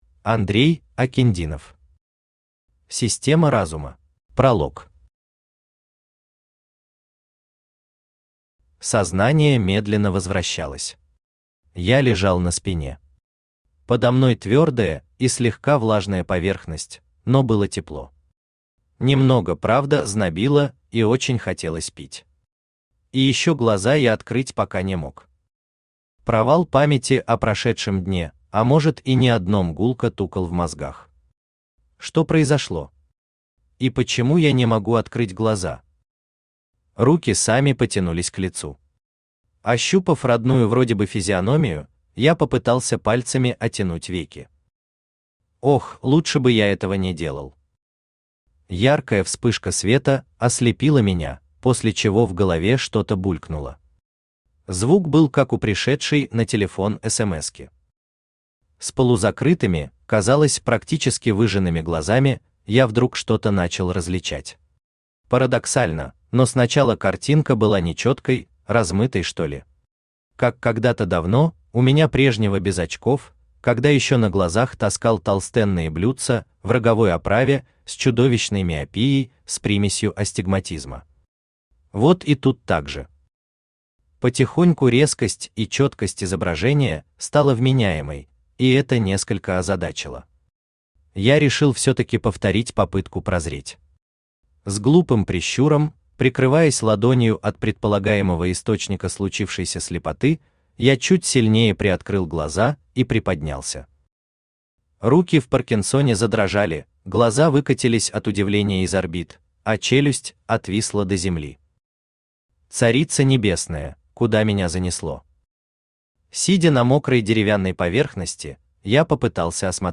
Aудиокнига Система Разума Автор Андрей Геннадьевич Акиндинов Читает аудиокнигу Авточтец ЛитРес.